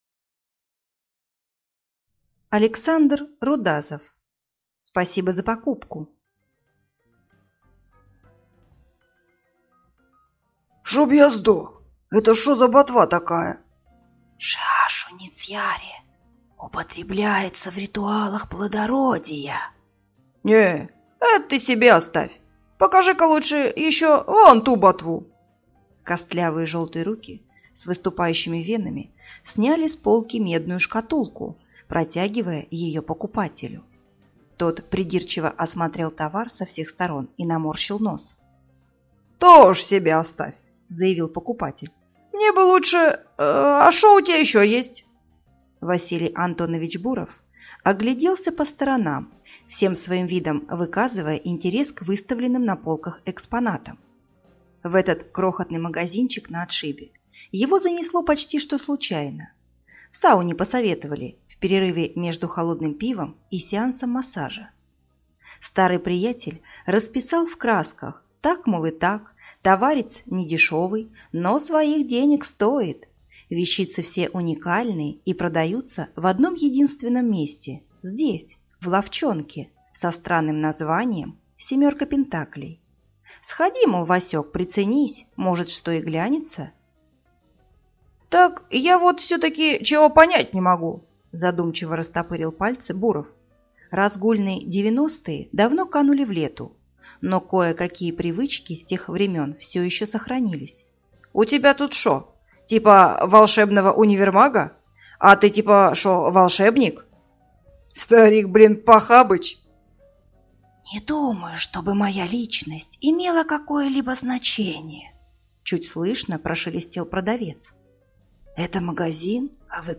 Аудиокнига Спасибо за покупку | Библиотека аудиокниг